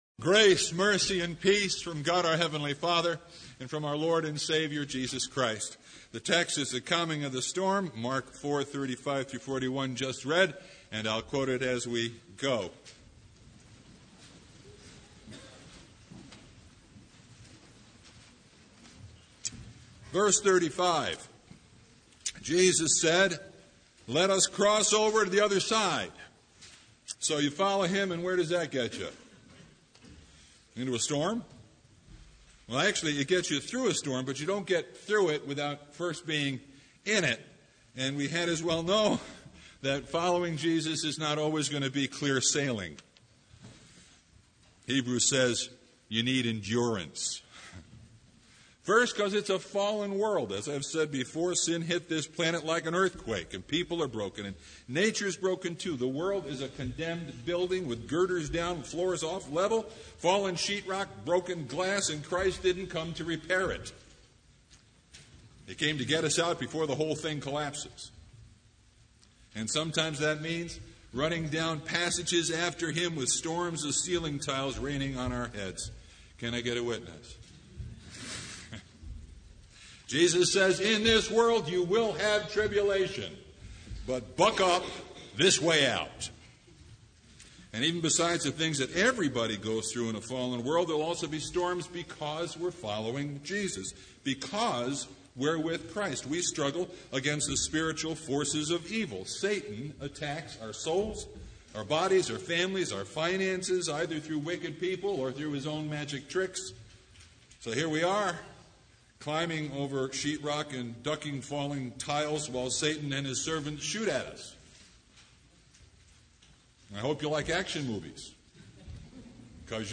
Sermon Only « Sermon from First Sunday in Martyrs’ Tide